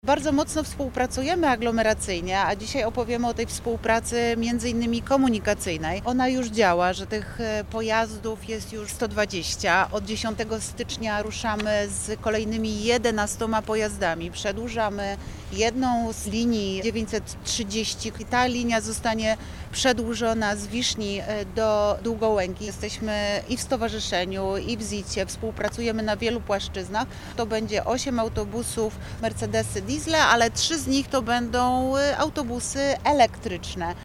Samorządy od lat pracują nad stworzeniem spójnego systemu podróży, mówi Renata Granowska, Wiceprezydent Wrocławia.